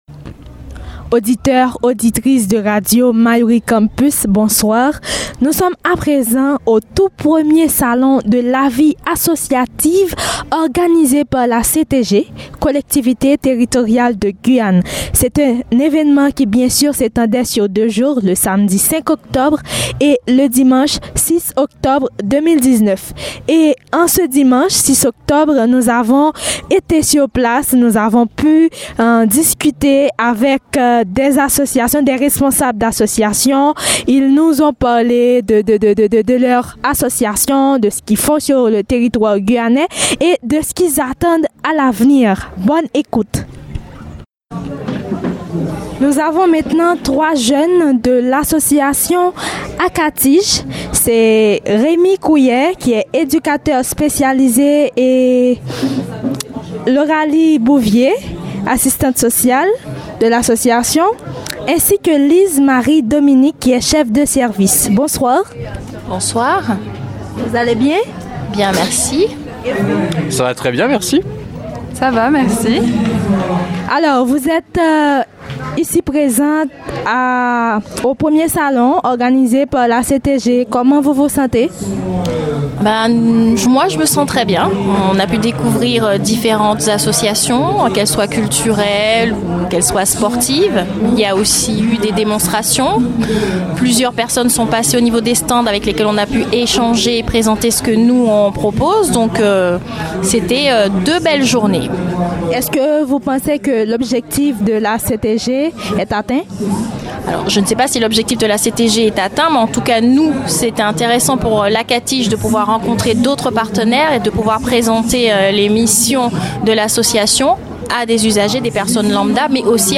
Premier salon de la vie associative de Guyane